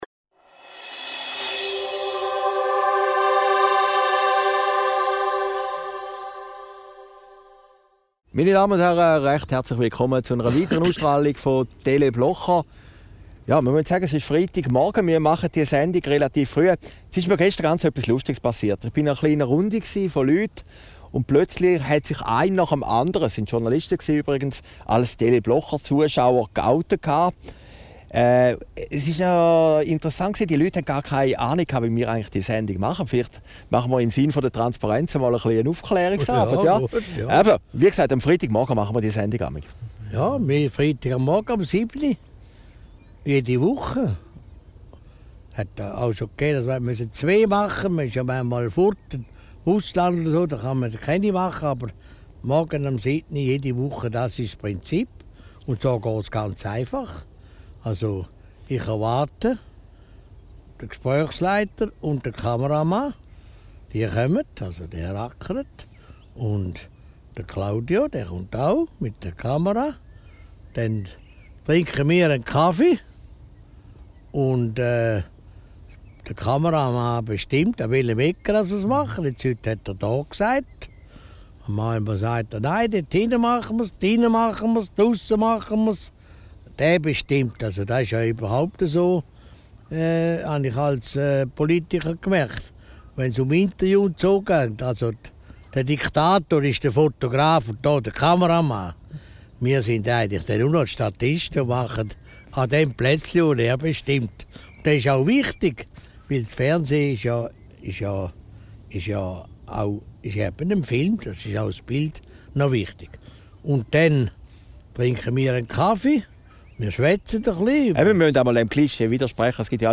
Video downloaden MP3 downloaden Christoph Blocher über die Machart von Teleblocher, das Geheimnis seiner Rhetorik und seine neue Rolle bei der Basler Zeitung Aufgezeichnet in Herrliberg, 04. Juli 2014